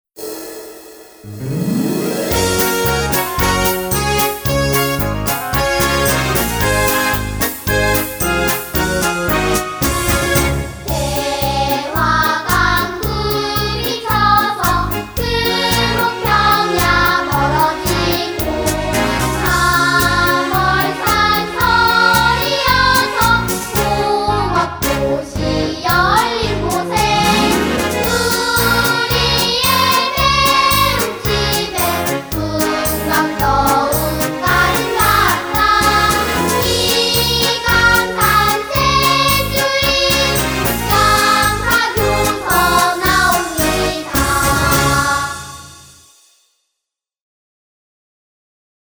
양사초등학교 교가 음원 :울산교육디지털박물관